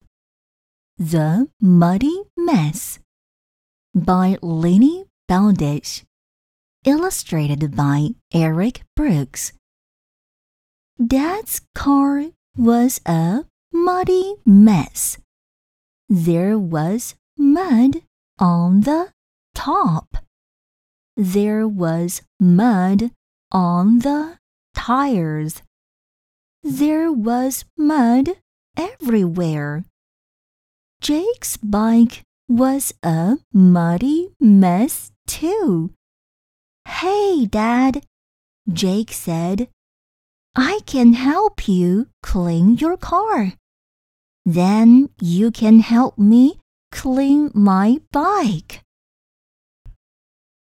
儿童绘本故事女英12号
年轻时尚 英文配音